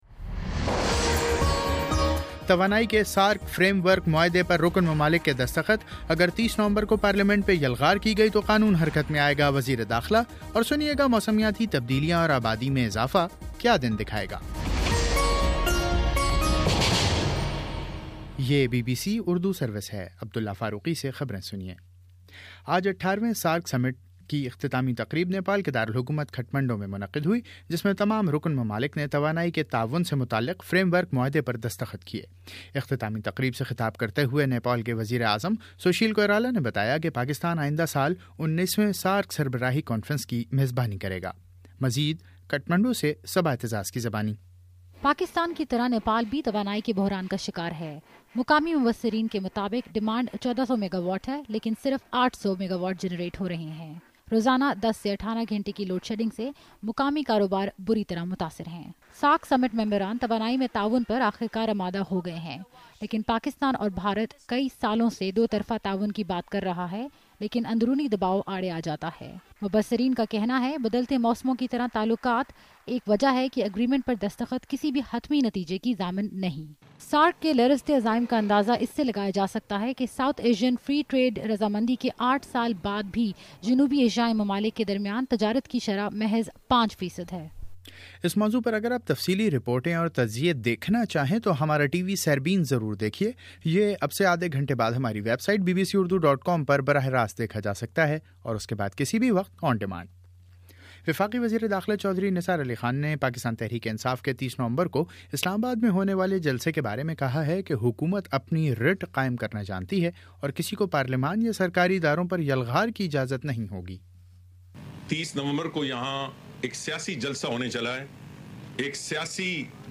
نومبر 27: شام سات بجے کا نیوز بُلیٹن
دس منٹ کا نیوز بُلیٹن روزانہ پاکستانی وقت کے مطابق صبح 9 بجے، شام 6 بجے اور پھر 7 بجے۔